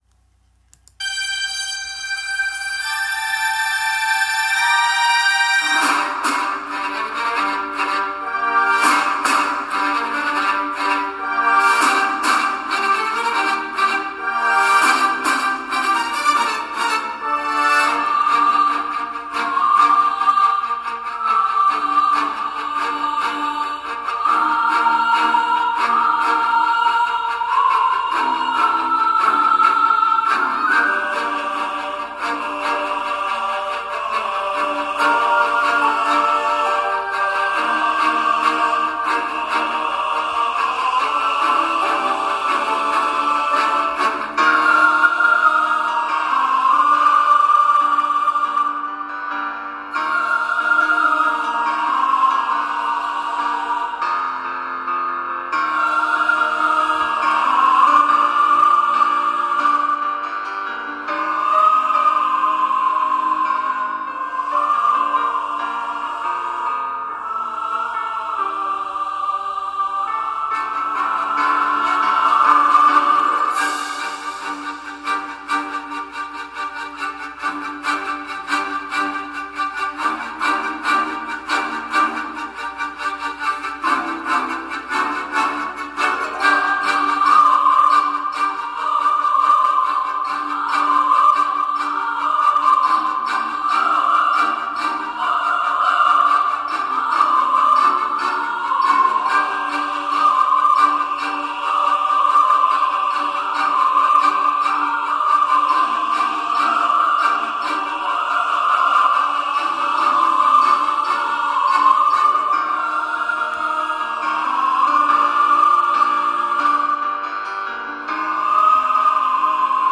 SATB
This is a piano reduction.
Voicing/Instrumentation: SATB
Choir with Soloist or Optional Soloist